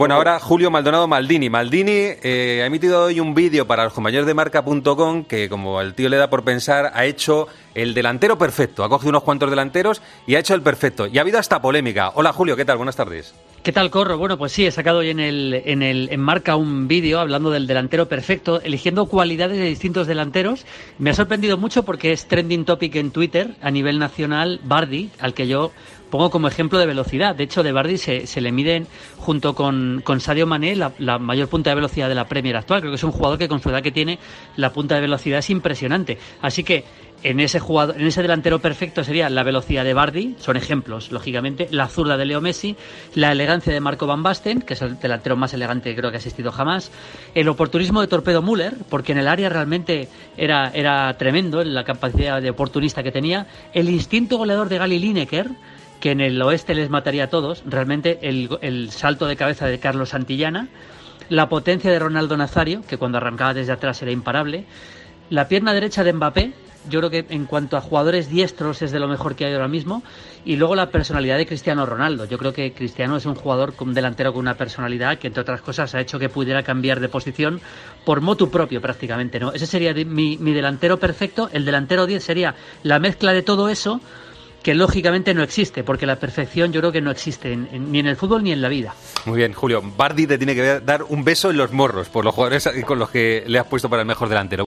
Julio Maldonado 'Maldini' ha utilizado los micrófonos de Deportes COPE este jueves para responder a quienes han hecho de Vardy 'Trending Topic' durante la mañana en Twitter, como consecuencia del último vídeo de opinión que nuestro especialista en fútbol internacional ha hecho en Marca, y en el que explica que, en su modelo de delantero ideal, estaría la velocidad de Jamie Vardy.